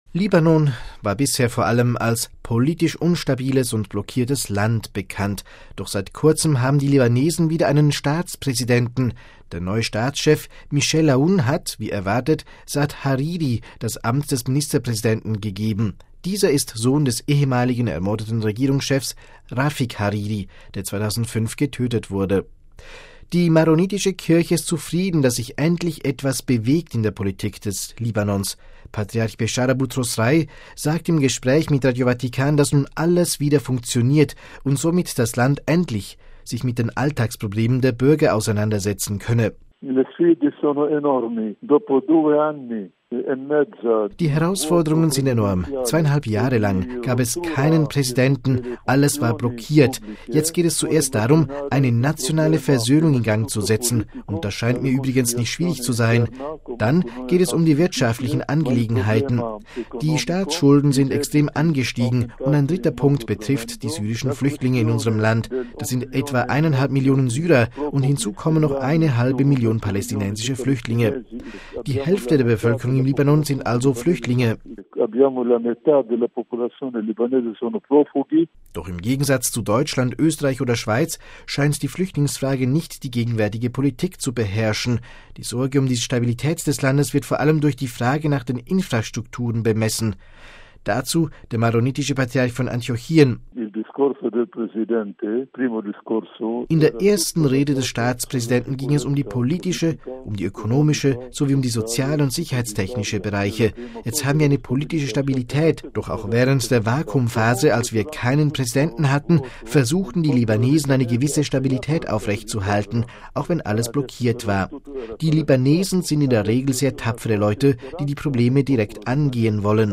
Die maronitische Kirche ist zufrieden, dass sich endlich was bewegt in der Politik des Libanons. Patriarch Bechara Boutros Rai sagt im Gespräch mit Radio Vatikan, dass nun „alles wieder funktioniert“ und somit das Land endlich sich mit den Alltagsproblemen der Bürger auseinandersetzen könne.